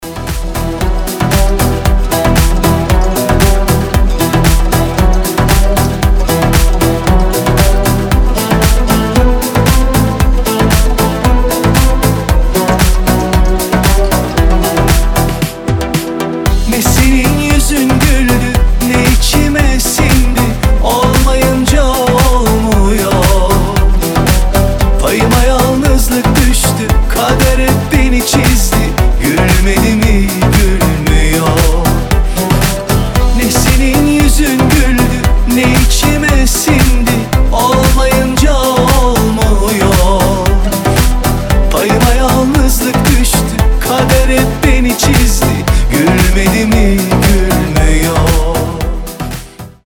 поп
гитара
красивые
мелодичные
восточные